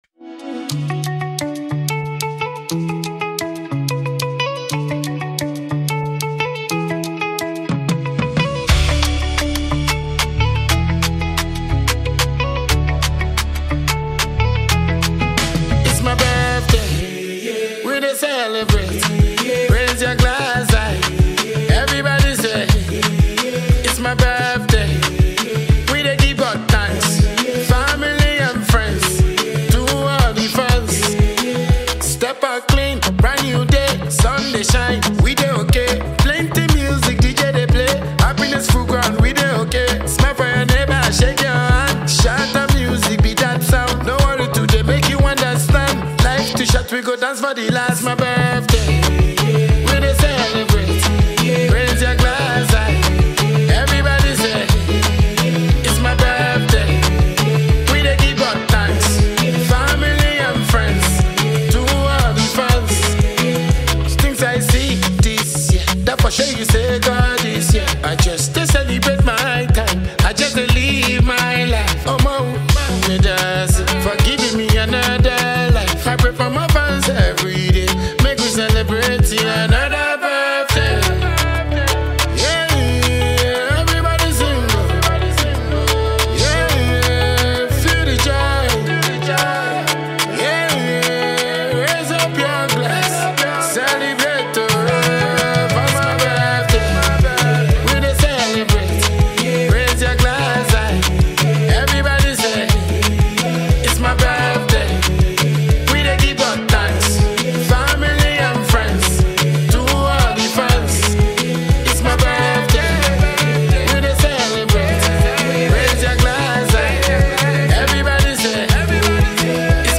a renowned and versatile dancehall singer